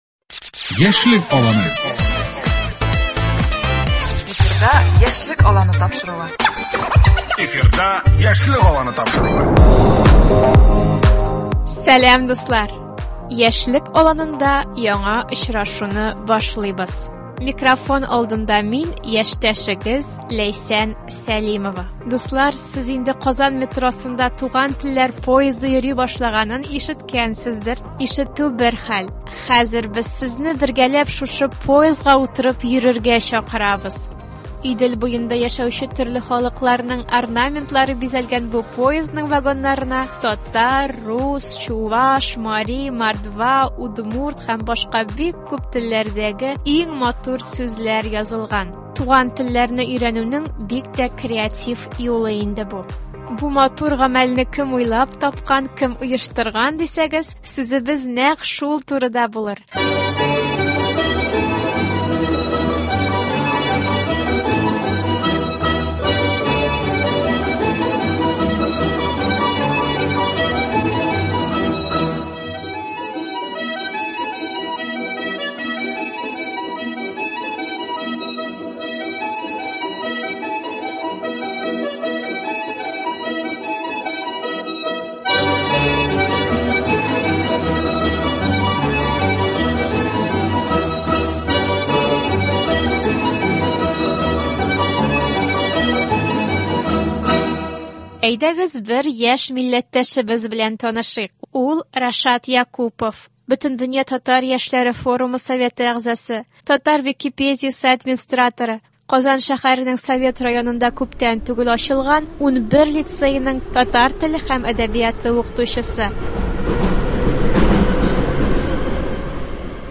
Игътибарыгызга вакыйга урыныннан репортаж тәкъдим итәбез